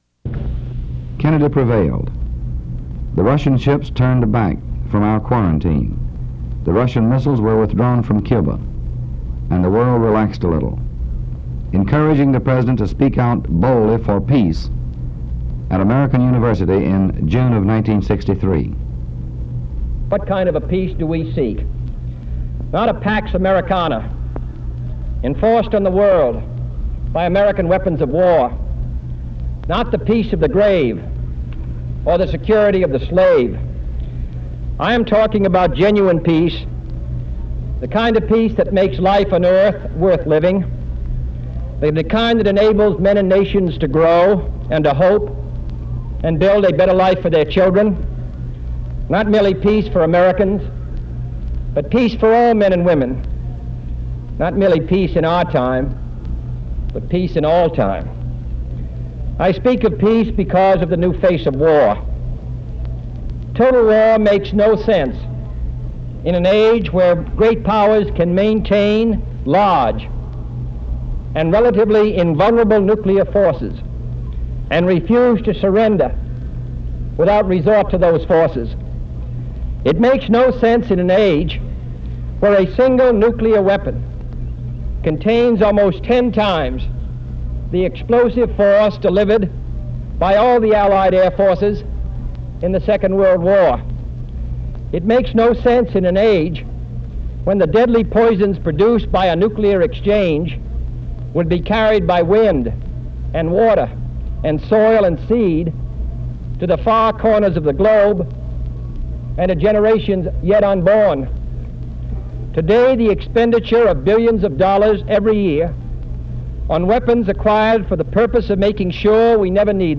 JFK's speech to the American University